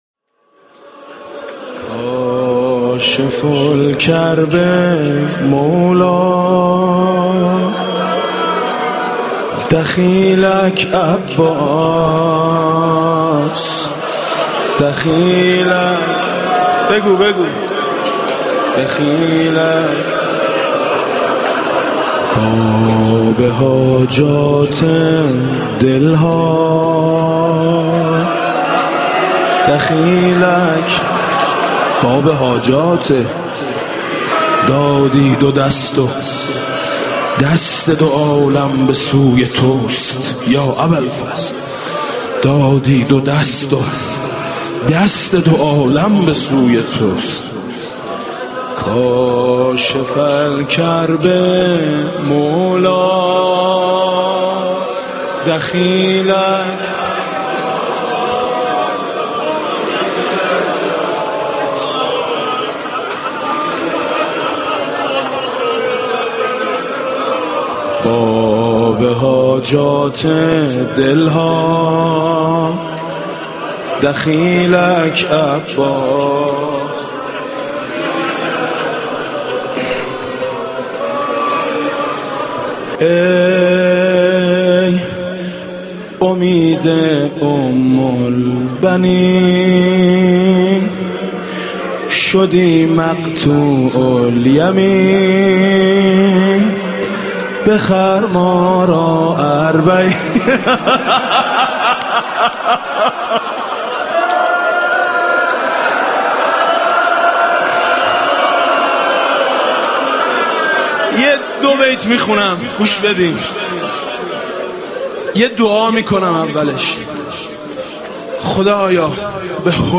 نعي الامام الحسين بصوت ميثم مطيعي (جديد)
اناشيد